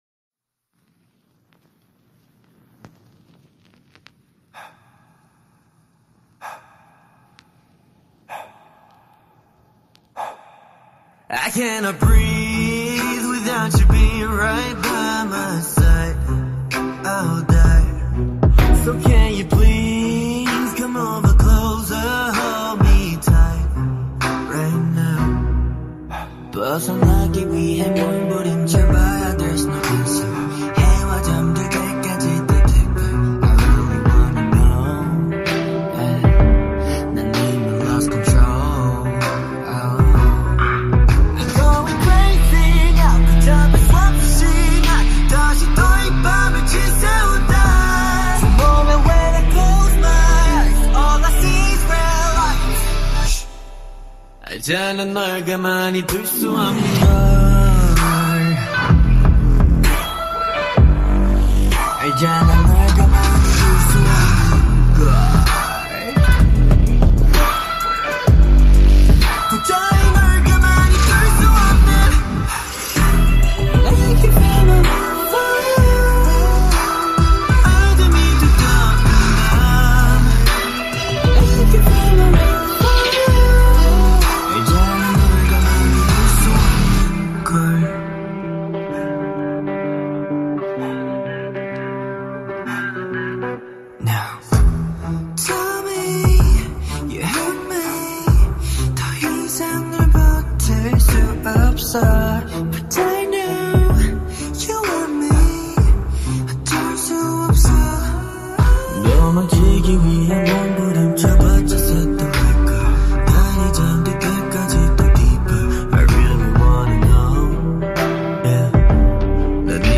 اهنگ شانزده بعدی خارجی ترسناک بیس دار جوون پسند مخصوص هنذفری